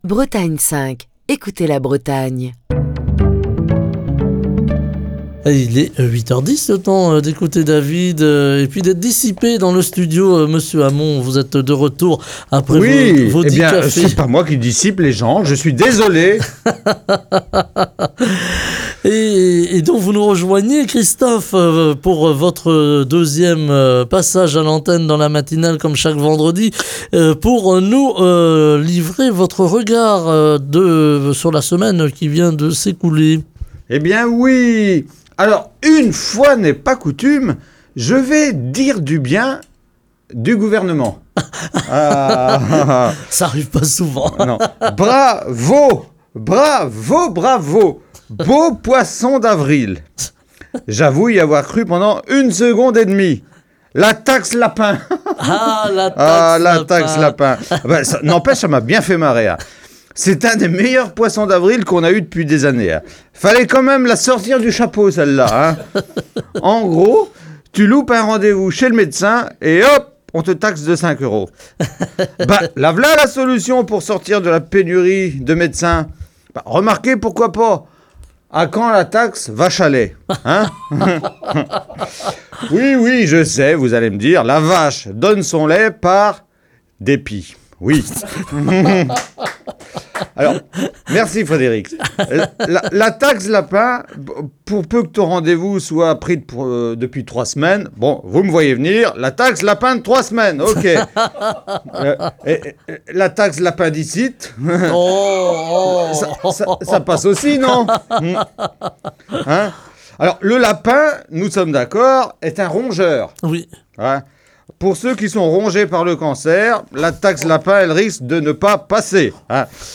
Chronique du 12 avril 2024.